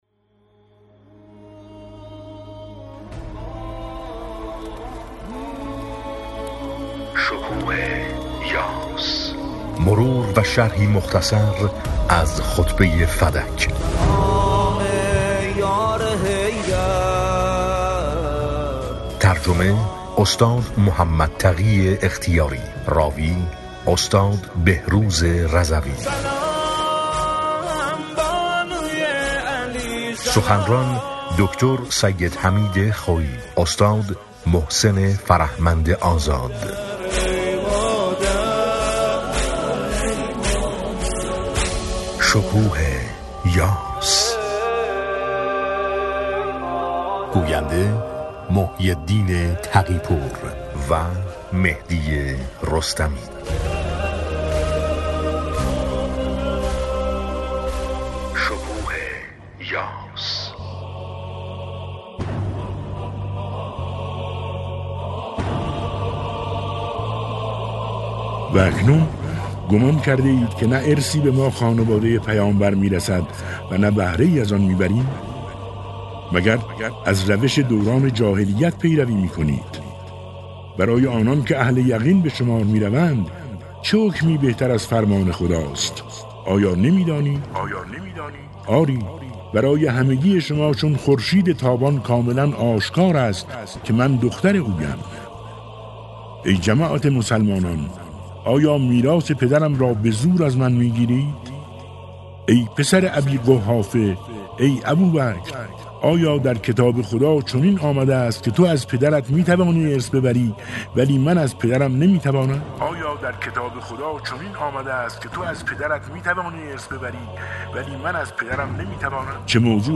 راوی : بهروز رضوی